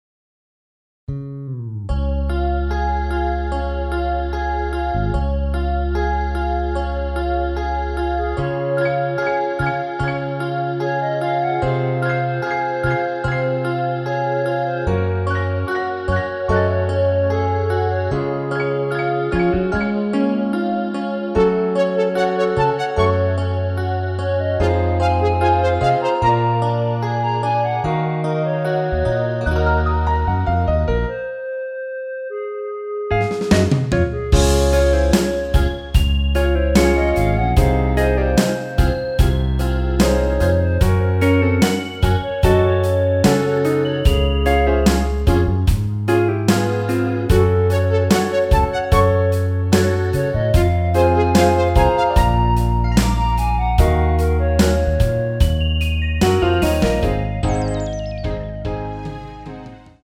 엔딩이 페이드 아웃이라 라이브하기 좋게 엔딩을 만들어 놓았습니다.
원키에서(+3)올린 멜로디 포함된 MR입니다.
Db
앞부분30초, 뒷부분30초씩 편집해서 올려 드리고 있습니다.
(멜로디 MR)은 가이드 멜로디가 포함된 MR 입니다.